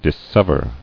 [dis·sev·er]